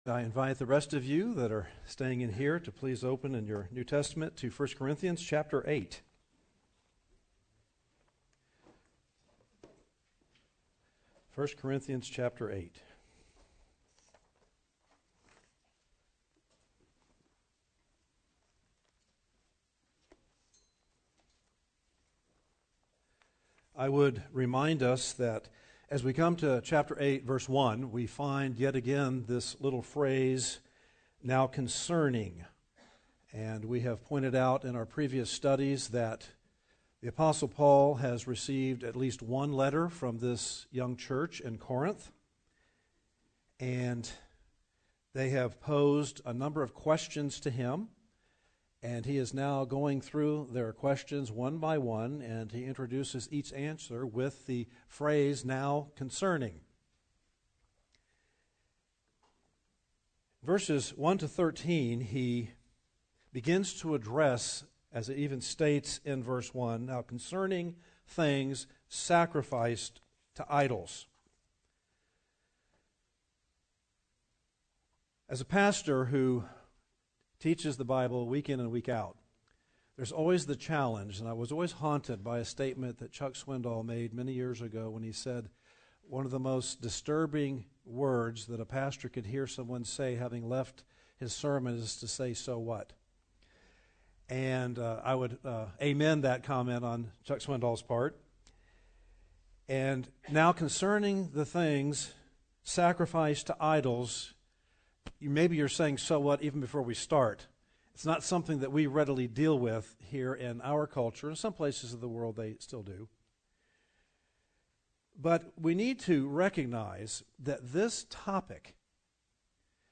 teaches from the series: 1 Corinthians, in the book of 1 Corinthians, verses 8:1 - 8:13